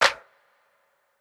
808 mafia clap.wav